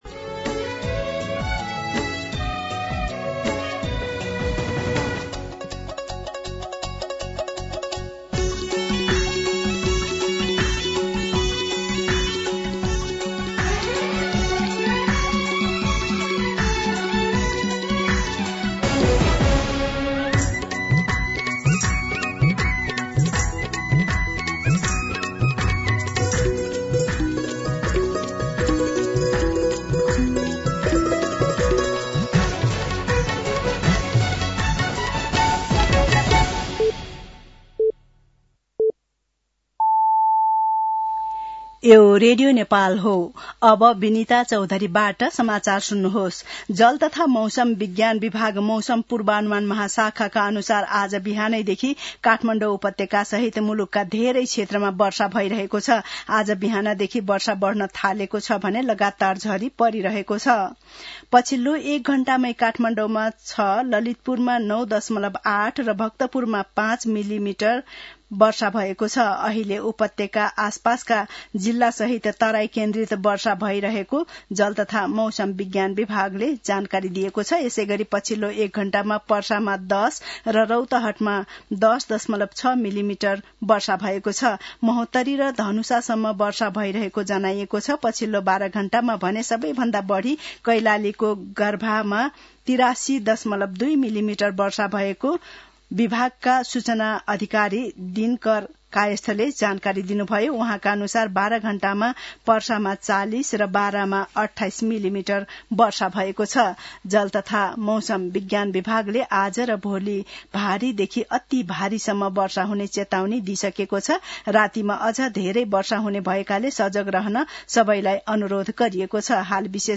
मध्यान्ह १२ बजेको नेपाली समाचार : १८ असोज , २०८२
12pm-News-2.mp3